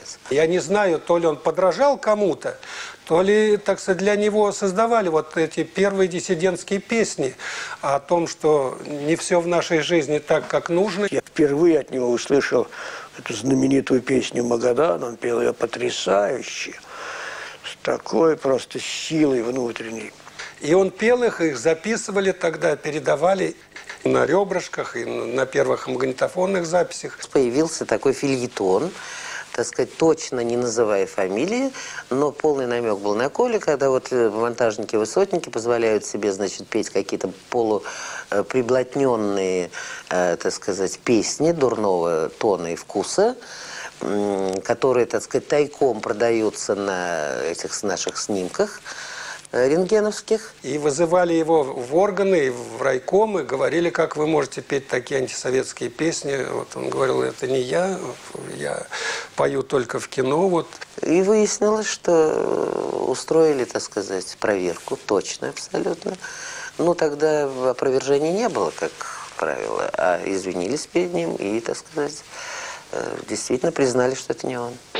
Вот, рипанул звук из этой передачи: